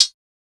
Closed Hats
TS HiHat_7.wav